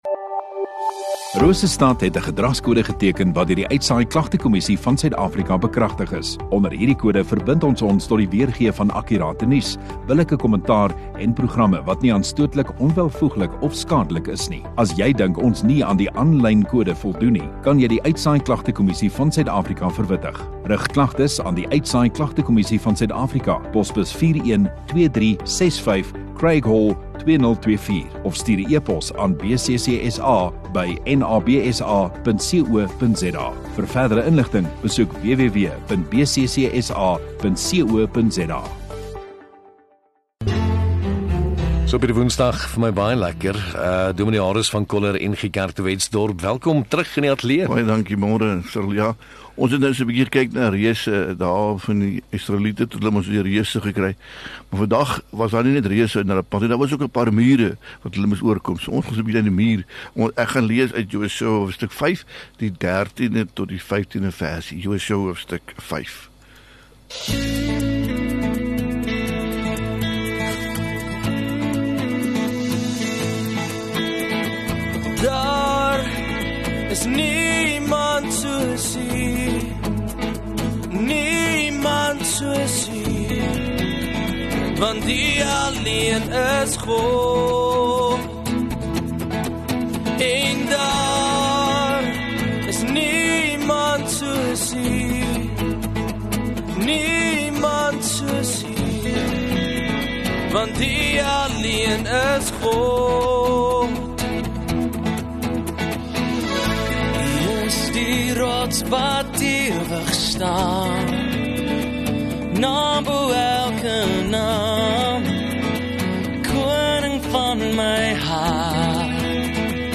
4 Oct Woensdag Oggenddiens